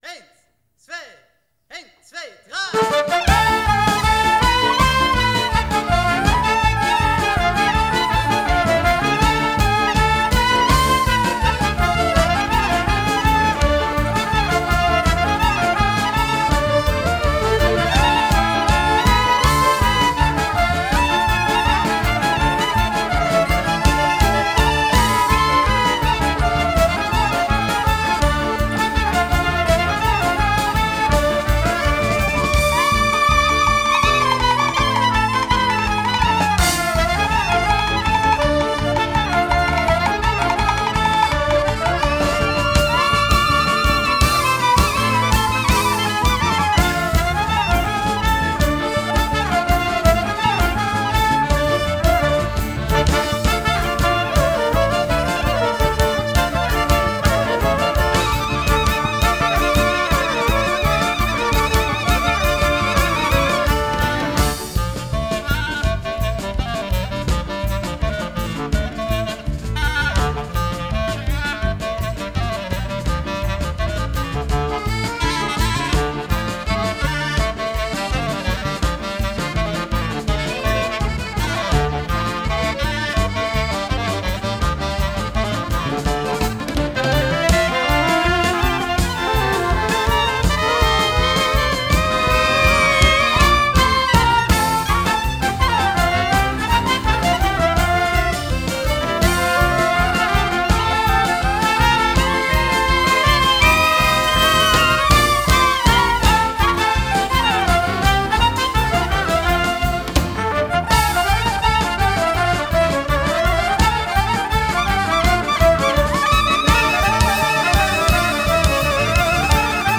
Sopransaxophon und Gitarre
traditionelle Klezmer-Musik ebenso wie moderne israelische Melodien und einzelne osteuropäische Stücke
Das Duo hat nicht die Absicht, eine wie auch immer verstandene Authentizität zu schaffen, sondern durch eine Wanderung zwischen Folk und Jazz und die liebevolle Auswahl schöner Melodien und Harmonien Spiel- und Hörfreude zu erzielen.